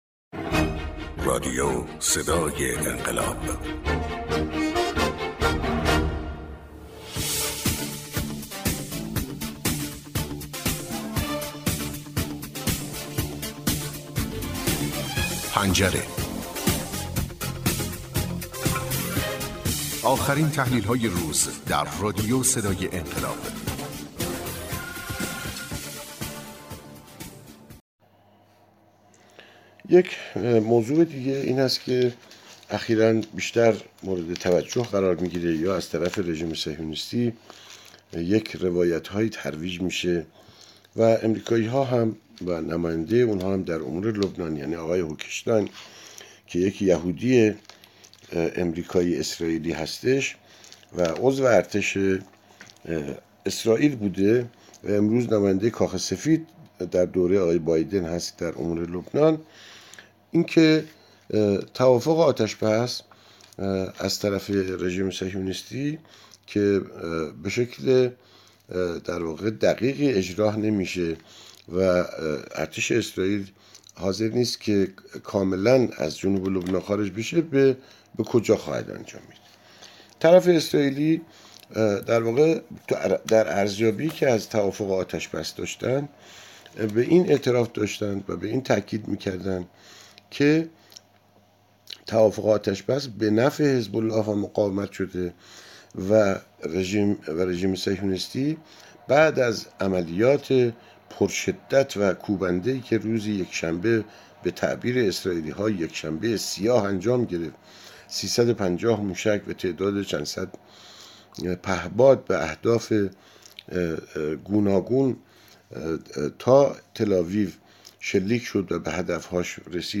تحلیل گر منطقه و بین الملل